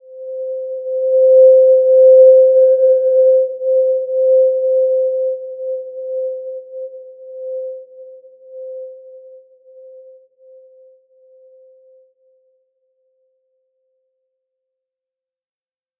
Simple-Glow-C5-p.wav